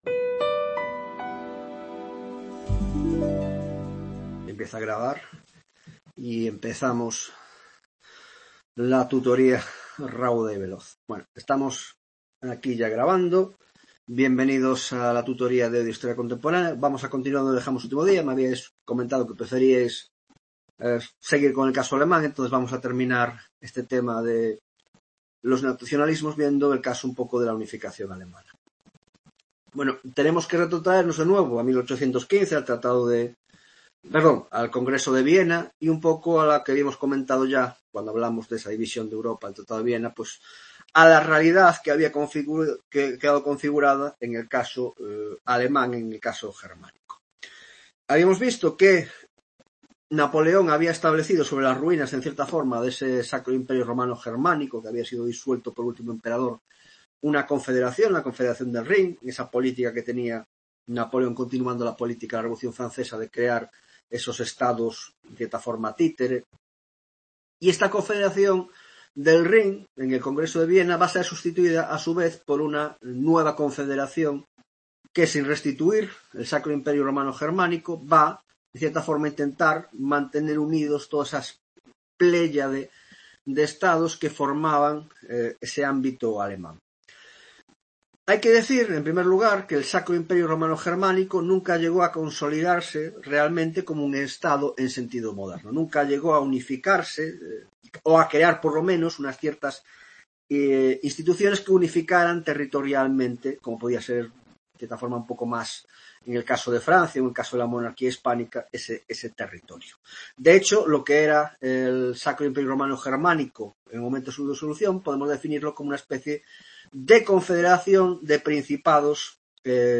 8ª tutoria de Historia Contemporánea - Napoleón III y los Nacionalismos (2ª parte - Unificación Alemana); El Sexenio Democrático (1ª parte): introducción: Crisis del Reinado de Isabel II; Revolución Gloriosa y la Monarquía de Amadeo de Savoya - Introducción